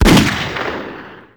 shotgunShot.wav